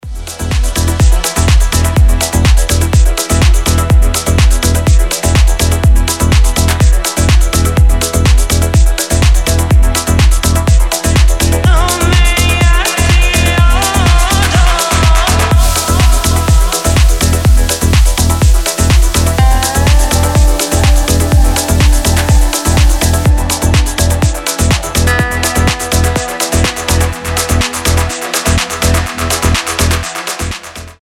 восточные
progressive house
этнические
organic house
Восточный мотив в основе этого динамичного рингтона